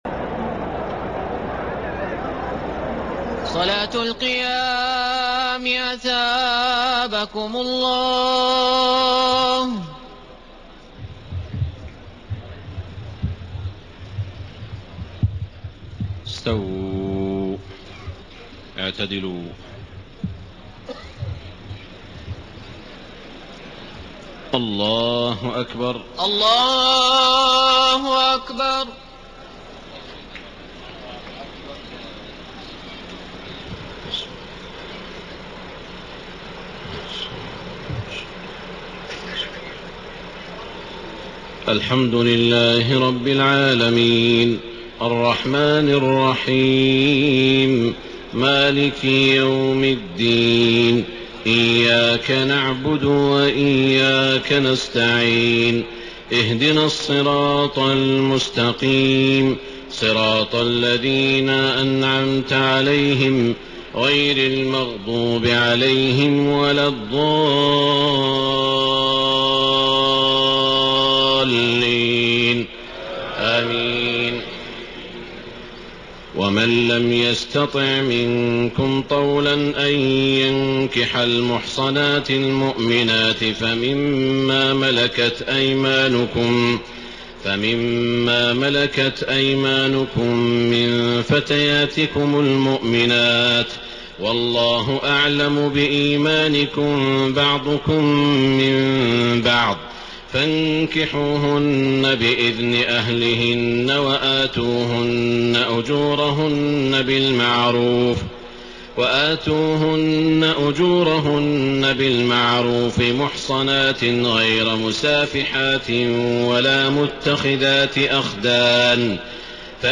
تهجد ليلة 25 رمضان 1433هـ من سورة النساء (25-99) Tahajjud 25 st night Ramadan 1433H from Surah An-Nisaa > تراويح الحرم المكي عام 1433 🕋 > التراويح - تلاوات الحرمين